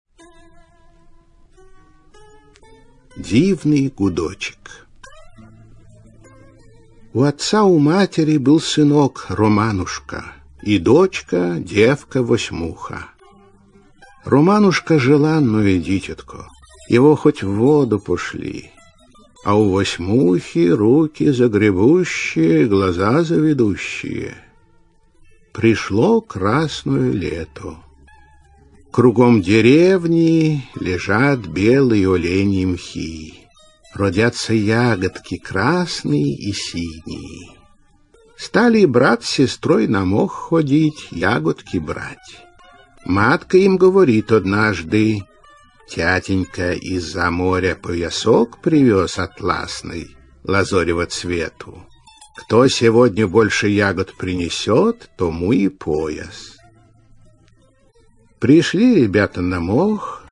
Аудиокнига Волшебное кольцо | Библиотека аудиокниг